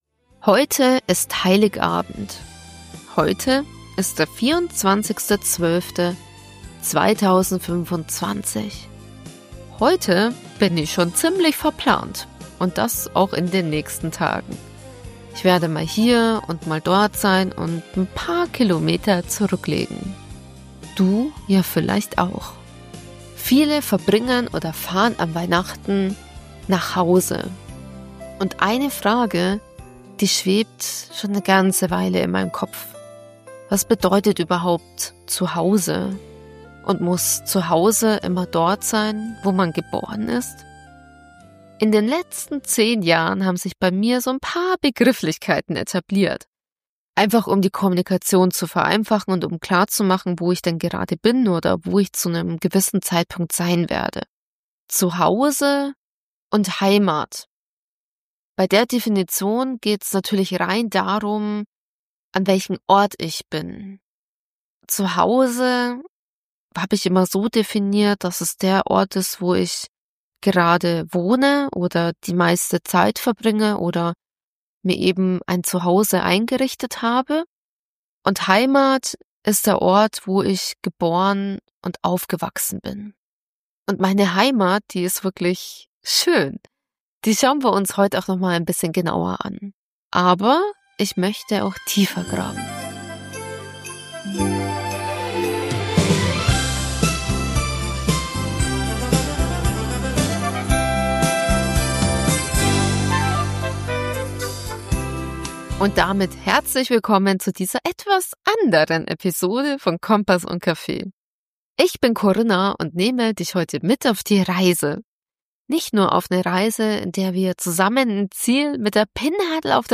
Zwischen persönlichen Gedanken und Gaststimmen stelle ich den Ort, indem ich aufgewachsen bin vor, empfehle die besten fränkischen Schmankerl und wo es sie gibt und stehe immer offen der Frage gegenüber, ob wir Heimat vielleicht immer wieder neu erschaffen dürfen.